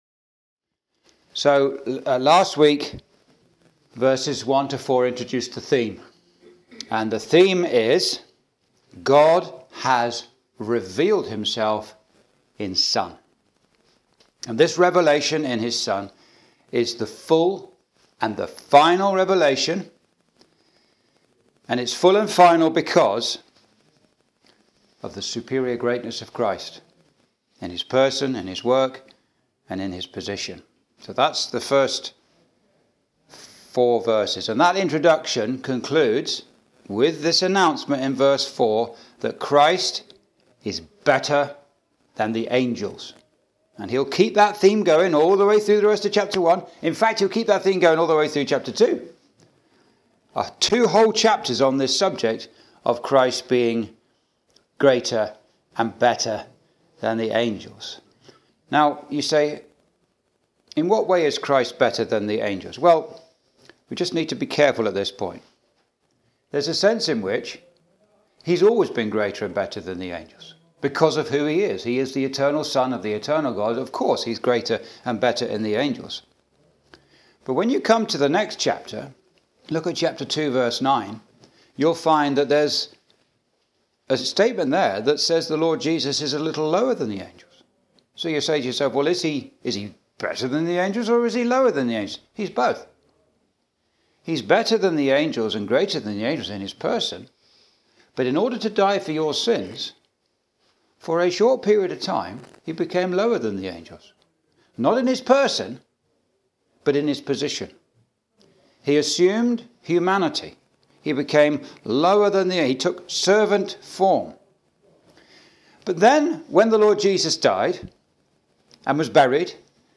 Message preached in Chalfont St Peter Gospel Hall, 2024
Verse by Verse Exposition